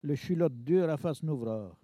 Localisation Saint-Hilaire-de-Riez
Catégorie Locution